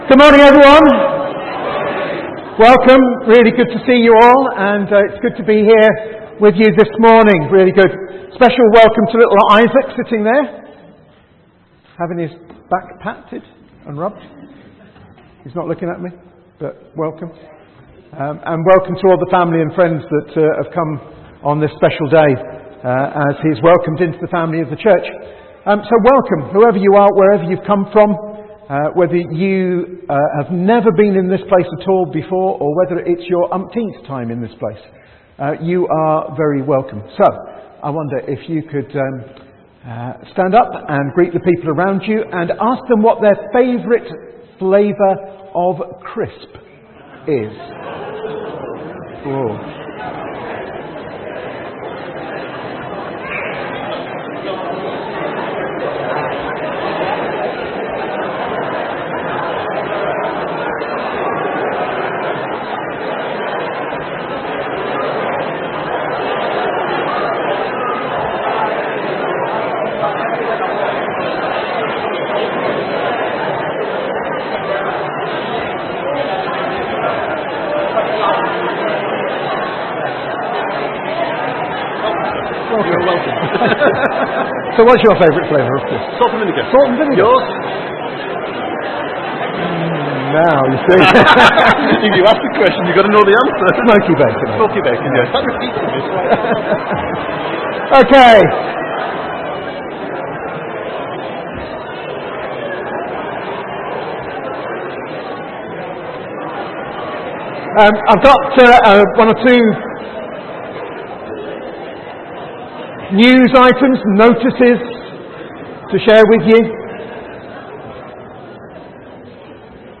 From Service: "10.00am Service"